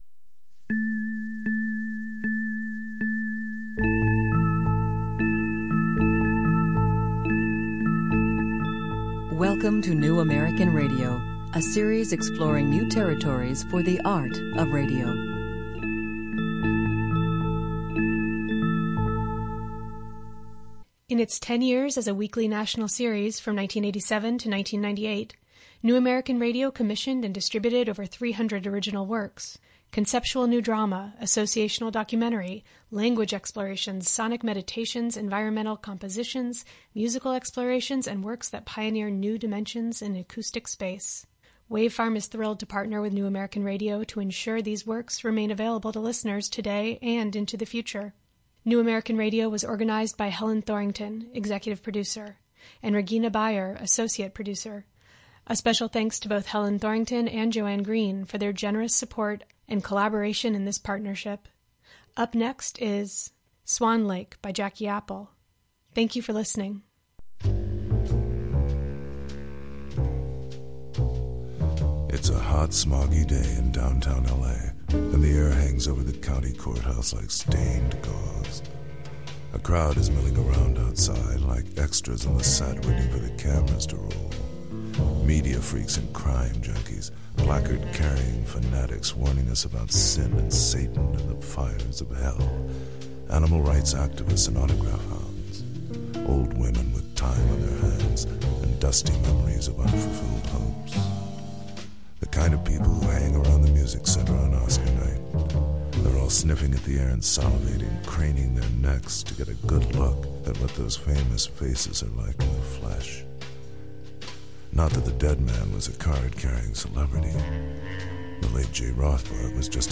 A satiric, ironic film noire "ballet" for radio, the original Swan Lake narrative is recast and resituated amidst the glittering surfaces and dark underside of late eighties Los Angeles where dreams are manufactured in medialand, art and entertainment tongue kiss, the third world coexists in a parallel realm, and the weather is unnatural.
Commissioned by New American Radio.